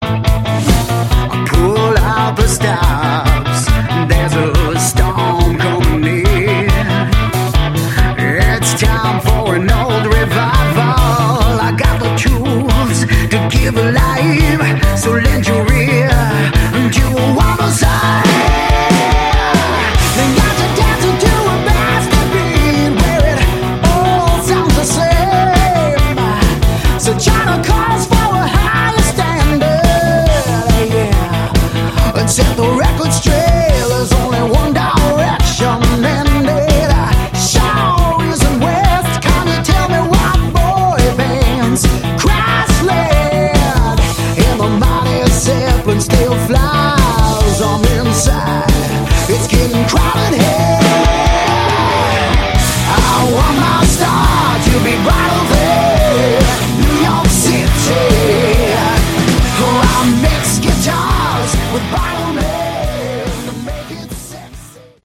Category: Hard Rock
bass
vocals
keyboards
guitar
drums